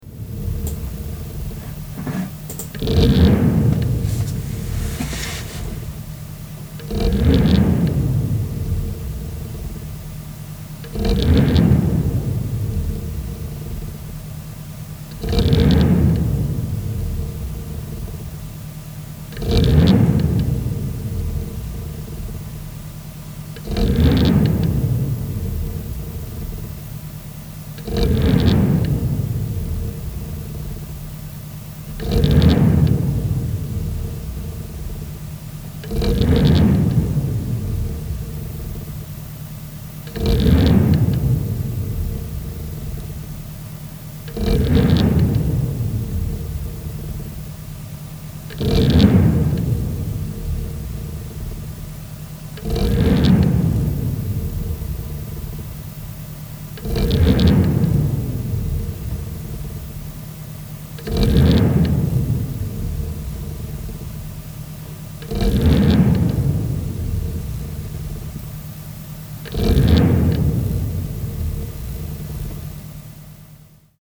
Seit dieser Zeit starten bei manchen Programmen (u.a. Photoshop, Audition, DaVinci Resolve) immer wieder hoch und drehen dann sofort runter.
Und dennoch dreht der Lüfter die ganze Zeit. Ich habe mal eine Datei mit den Lüftergeräuschen angehängt, nicht wundern über die Lautstärke, hab das Mikro direkt in das Gehäuse gelegt.